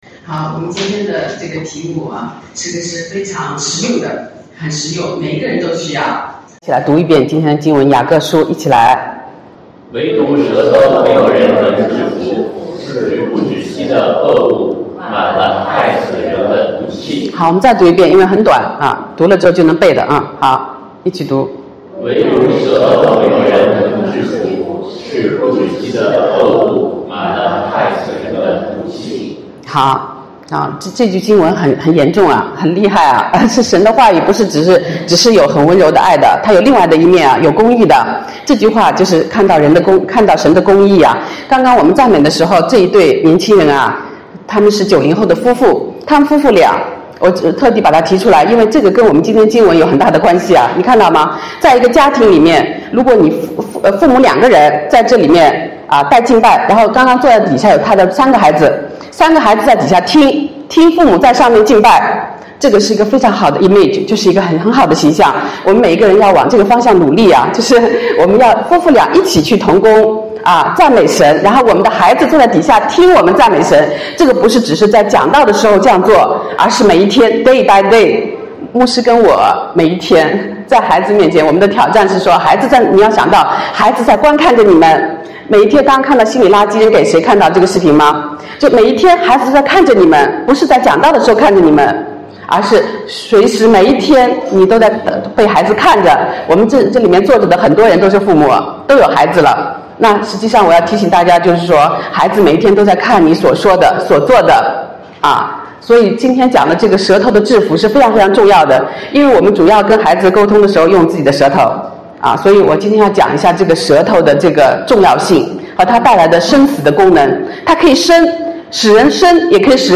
12月24日平安夜聚会